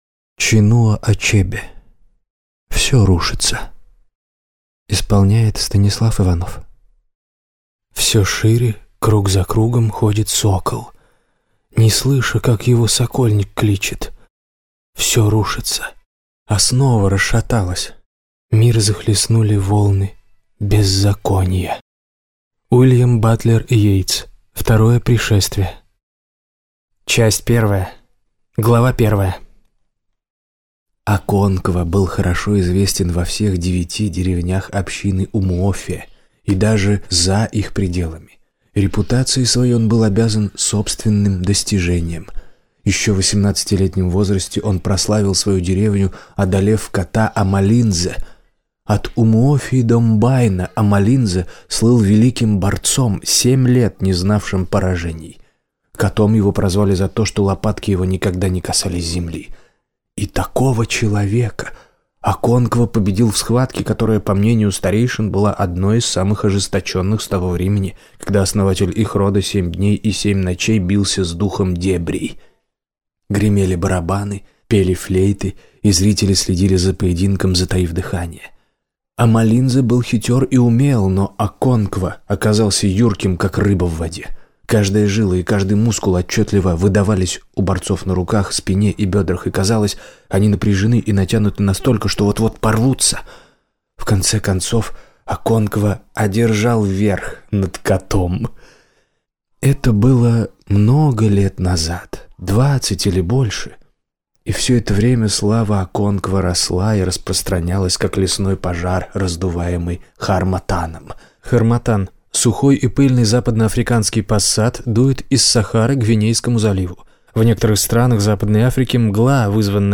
Аудиокнига Все рушится | Библиотека аудиокниг